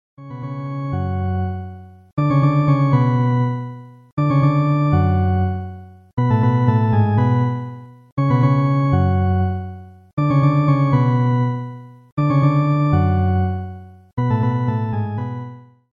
• Качество: 128, Stereo
Cover
инструментальные
медленные
мрачные
орган
Мелодия бессмертного хита на органе